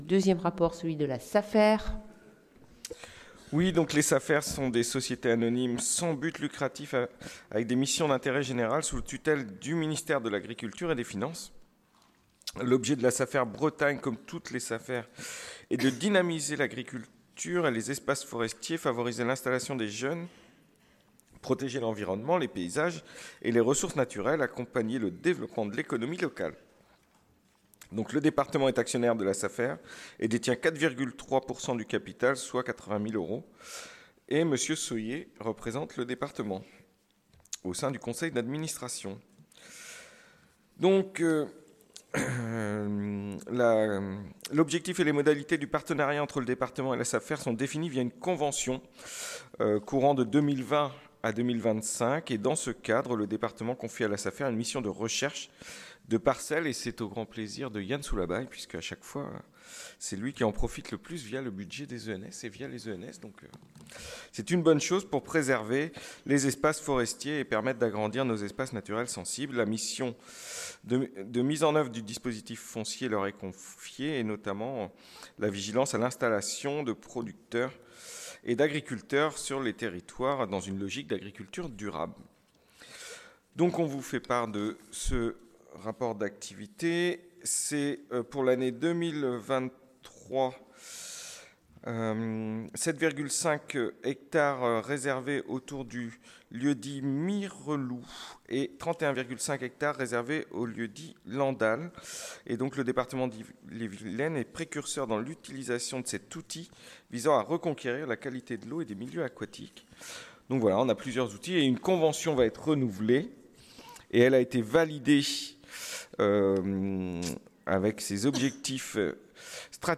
• Assemblée départementale du 14/12/23